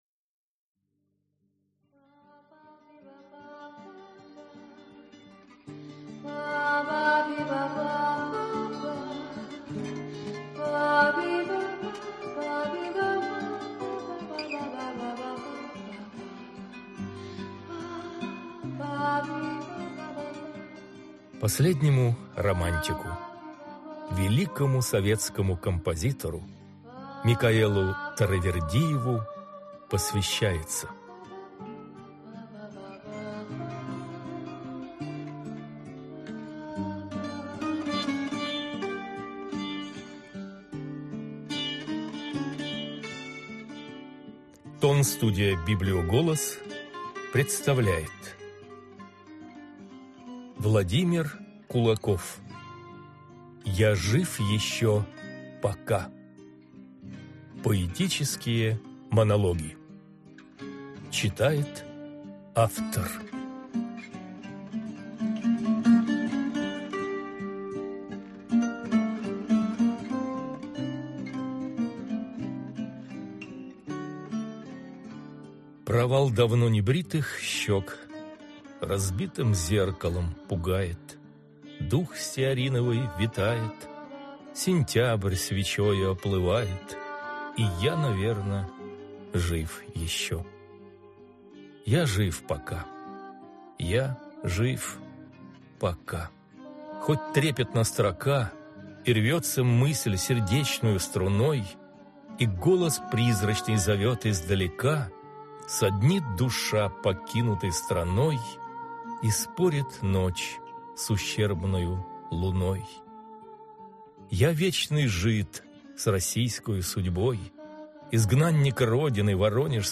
Аудиокнига Я жив ещё пока… (сборник) | Библиотека аудиокниг